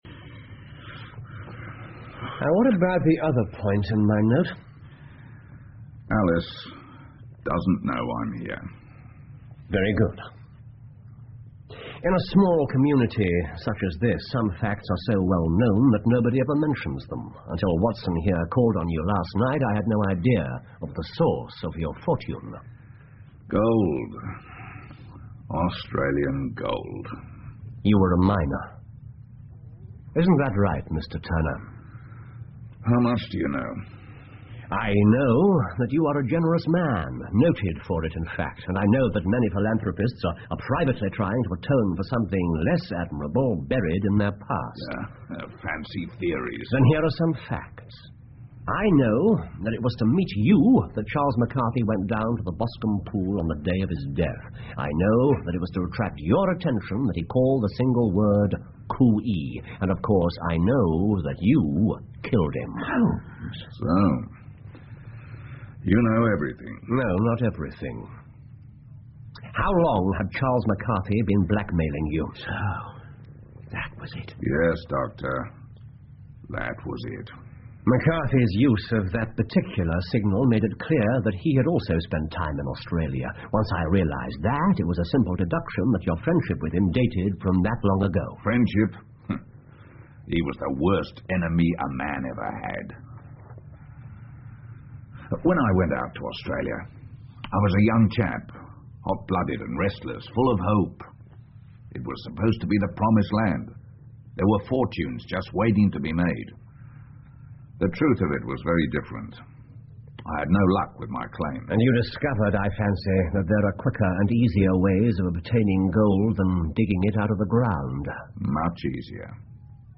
福尔摩斯广播剧 The Boscombe Valley Mystery 7 听力文件下载—在线英语听力室